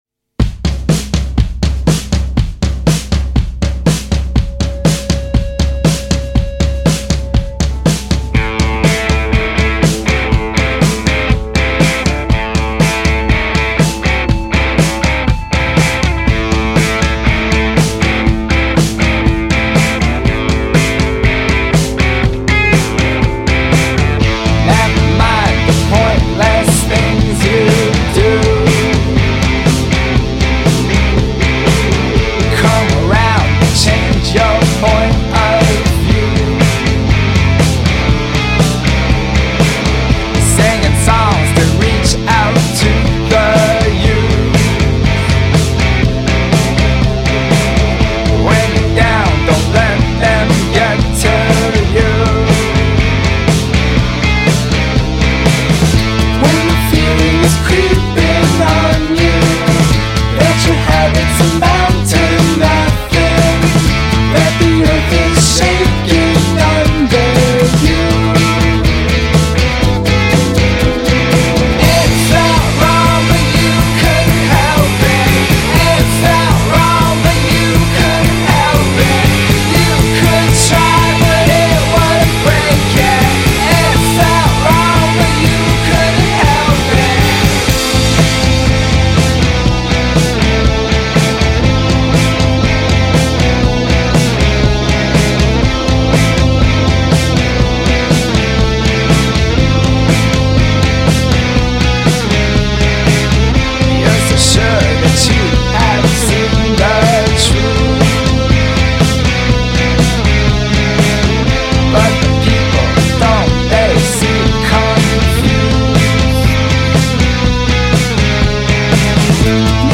A brooding, slinky affair
scuzzy Tel Aviv quartet
Understated, but bristling with the same electric energy
it’s a swampy call to arms for disaffected youth.